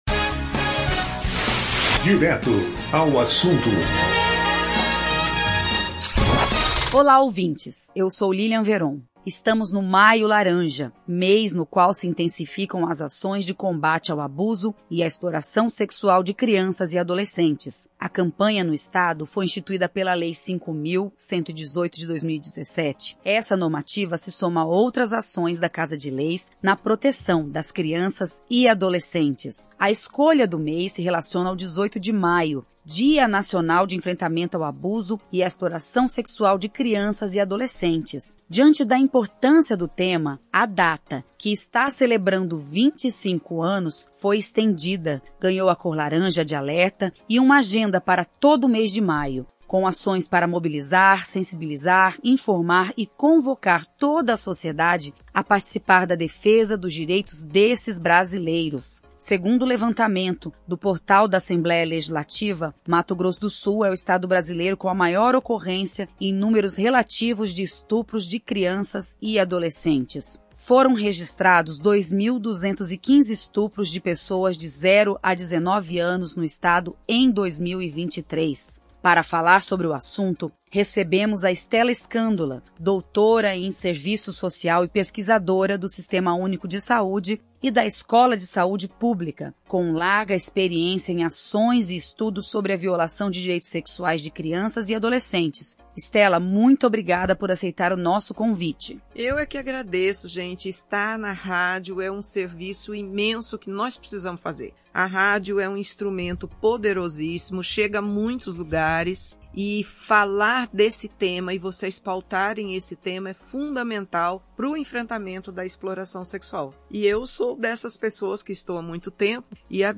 Especialista em combate a violência sexual contra crianças e adolescentes